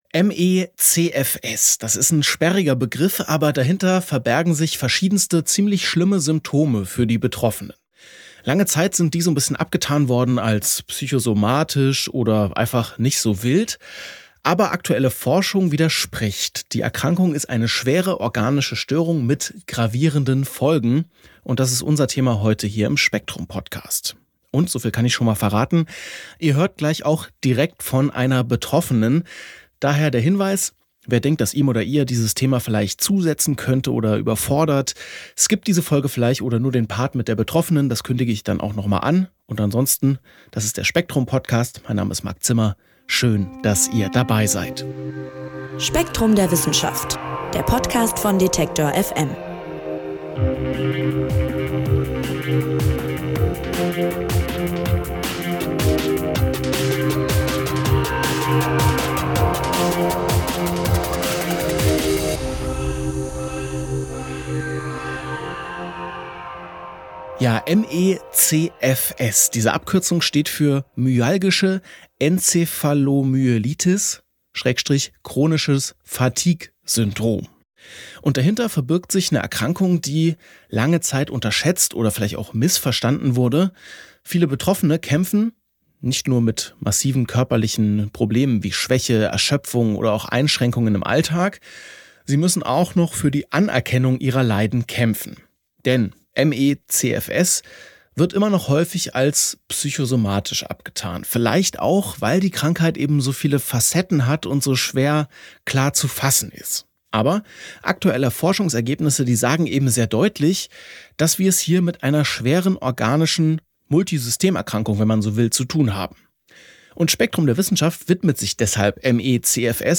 Komplexe Krankheit: Eine Betroffene berichtet, wie ME/CFS ihr Leben auf den Kopf gestellt hat. Wie stehen die Heilungschancen?